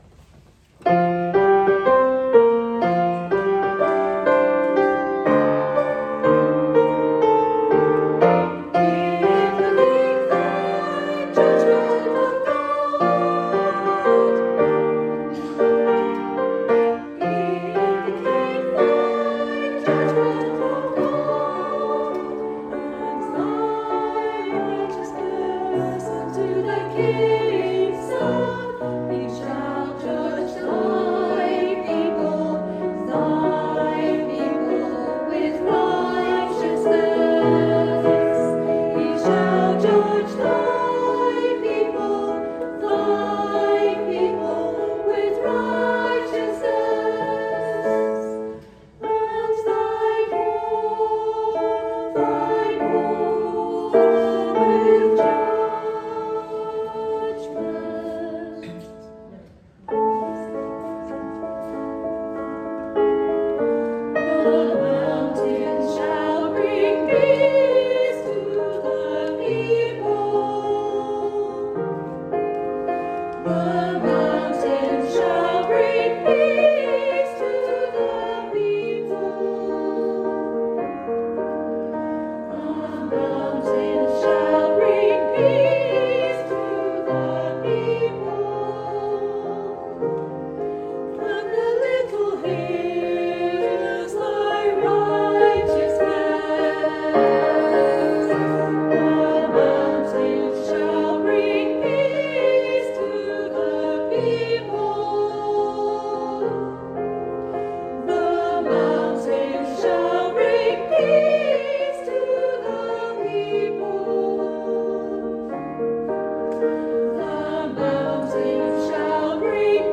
Good morning and welcome to our service of holy communion for Sunday 14 May.
The anthem sung whilst communion was distributed was “The Mountains Shall Bring Peace“, a setting of Ps 72:1-3 and Ps 149:1-3 by Joanna Forbes l’Estange, which was commissioned by the Royal School of Church Music to commemorate the coronation of King Charles III: